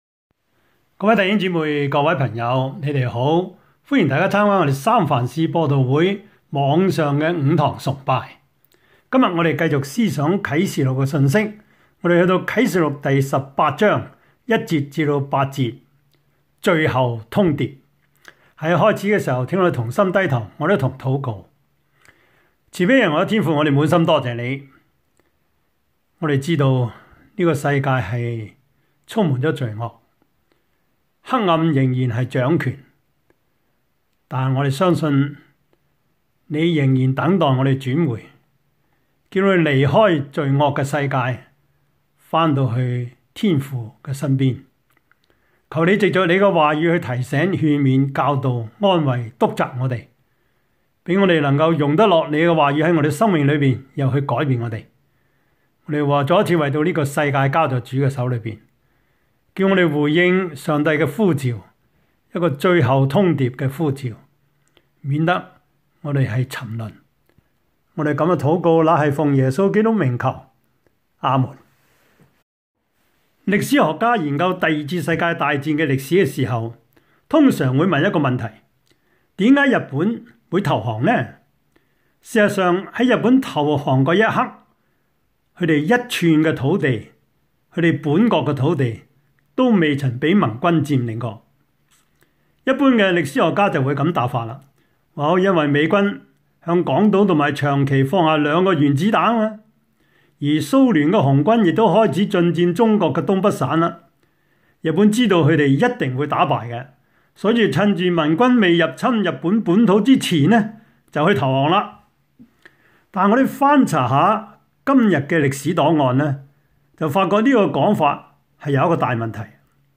Service Type: 主日崇拜